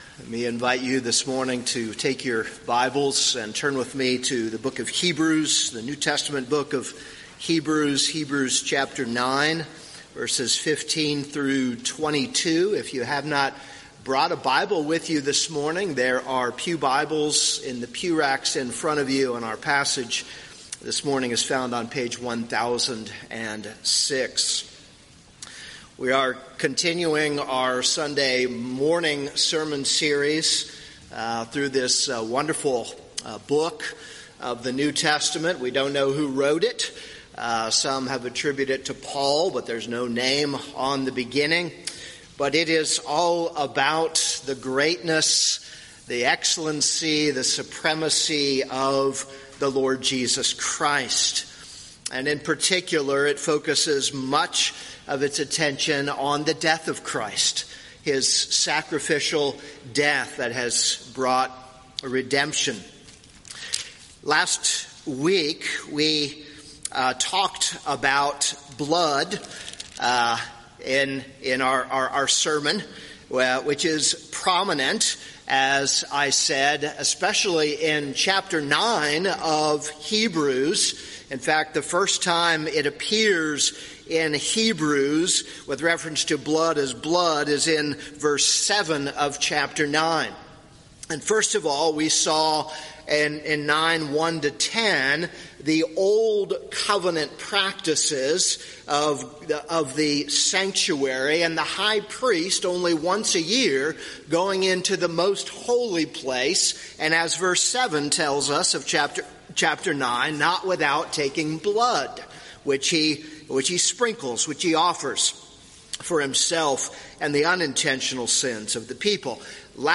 This is a sermon on Hebrews 9:15-22.